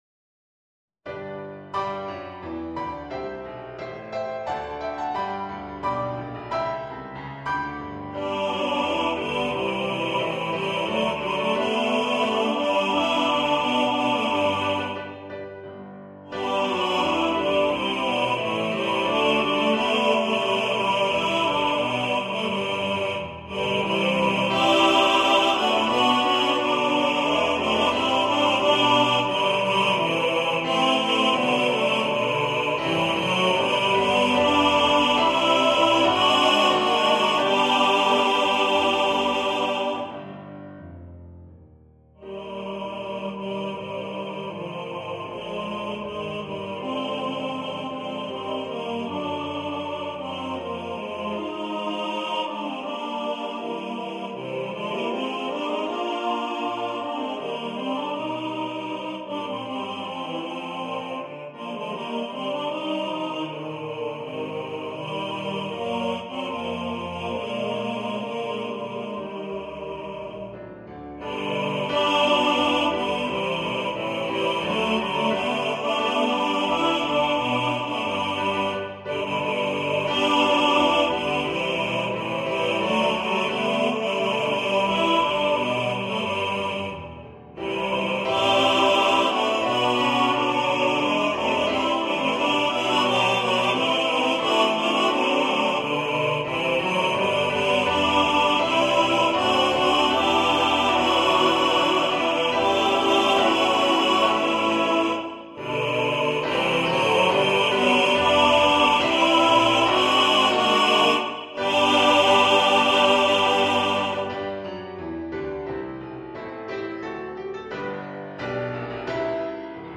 for TTBB choir
A Carol for Christmas for choir and orchestra or piano.
(Choir - Male voices)